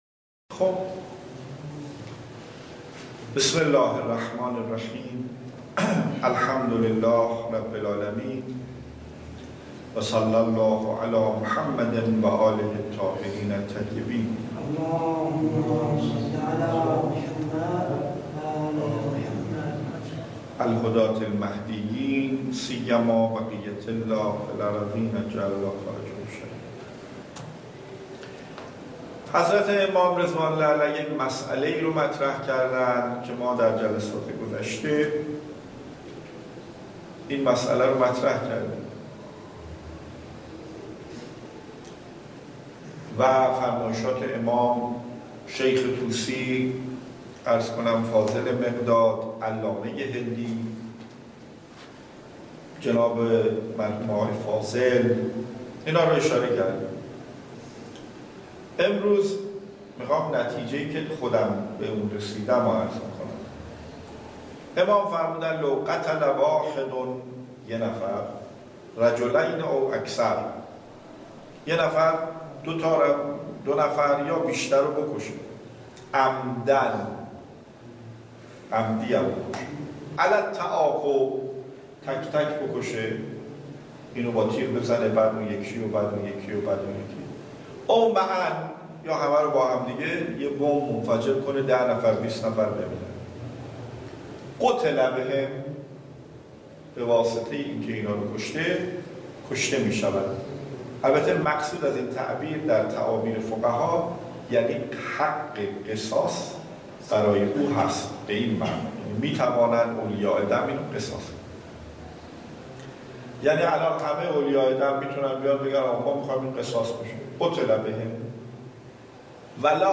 درس خارج فقه